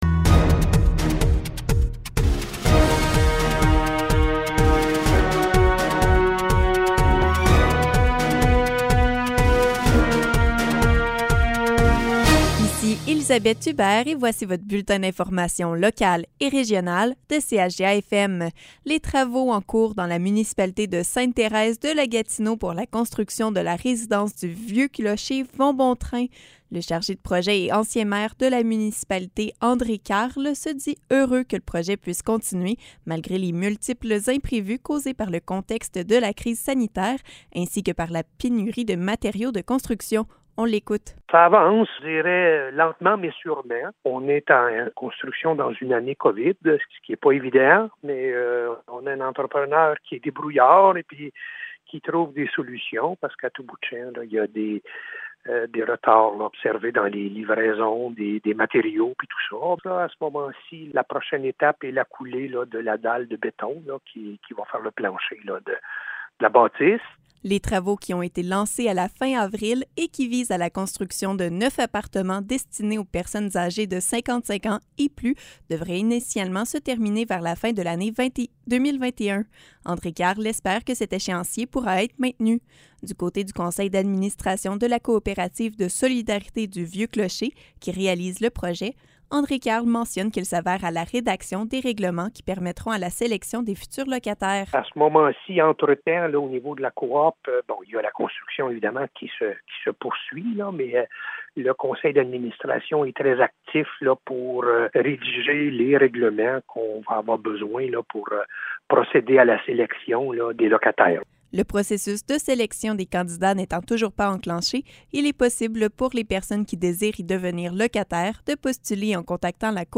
Nouvelles locales - 8 juillet 2021 - 12 h